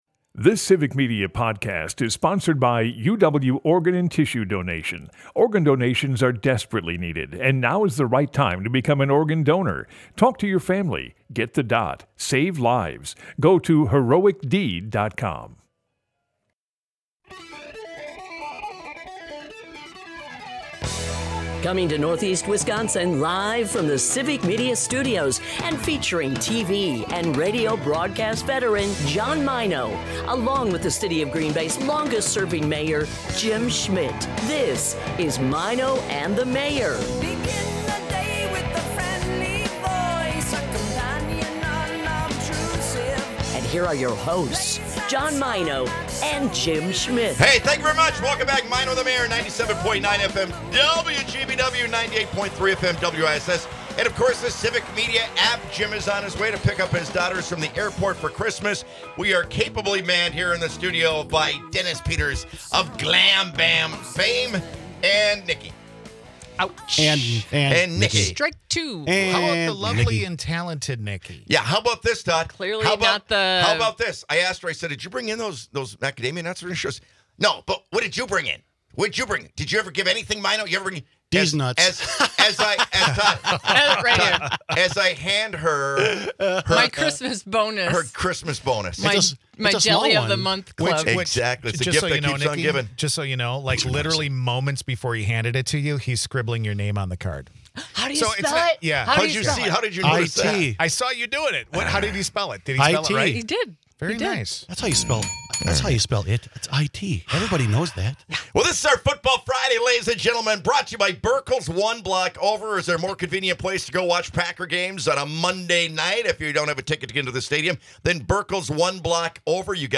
This week we feature former Green Bay quarterback Randy Wright! Randy always takes a very analytical view of the game and can talk about what the current team needs to do to make it to the playoffs and maybe even the big game! Plus, Friday means that we feature music in the Heights Pub & Parlor LIVE Music series!